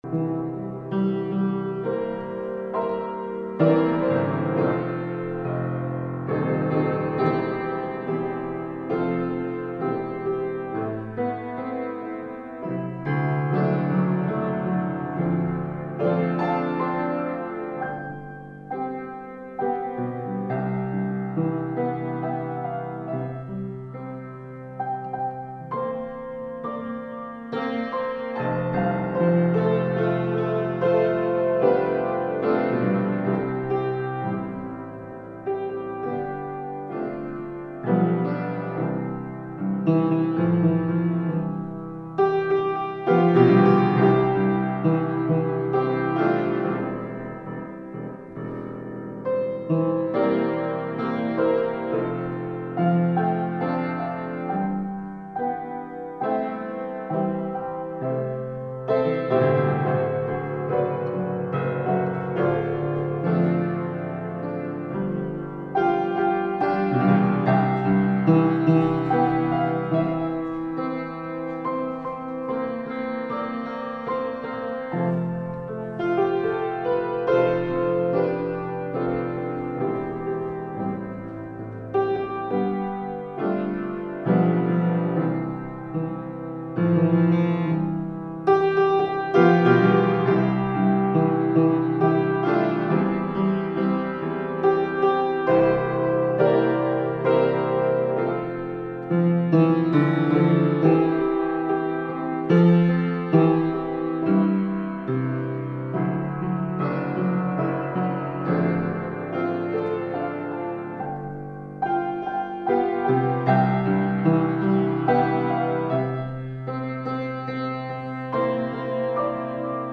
Service Type: Wednesday Night Bible Study